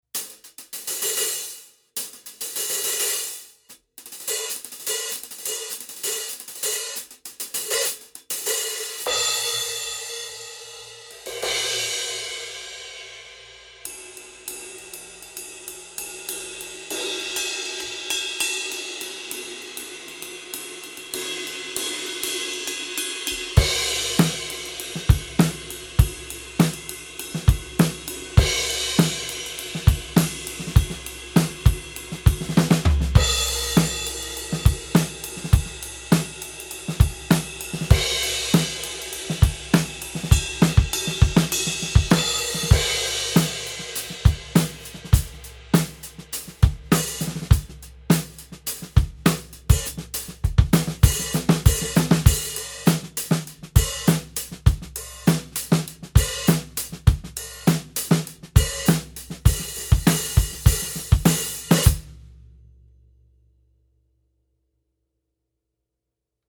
Product Close-Up
Our review models feature heavy hammer markings, unique-looking bands of grinded grooves and traditional lathing, and a heavily anodized finish, all of which adds up to some of the most distinctive-looking and unconventional-sounding cymbals we’ve ever come across.